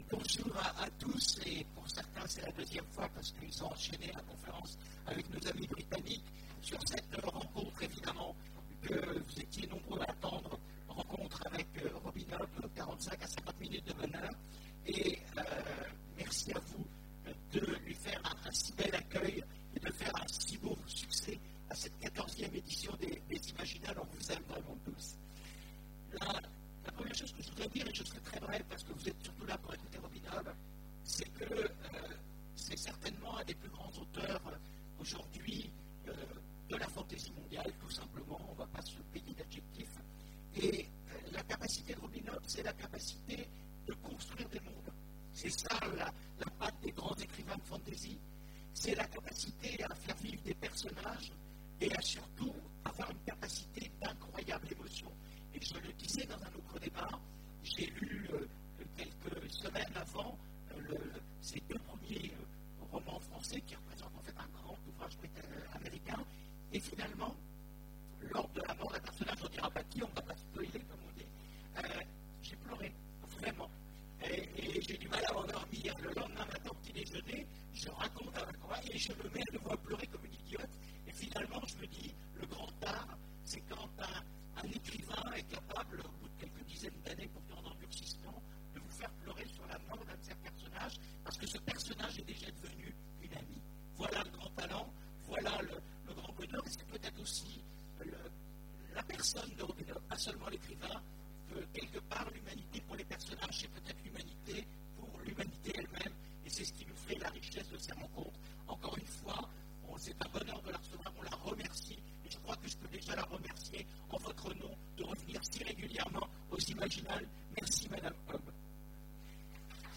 Imaginales 2015 : Entretien avec... Robin Hobb
Robin Hobb Télécharger le MP3 à lire aussi Robin Hobb Genres / Mots-clés Rencontre avec un auteur Conférence Partager cet article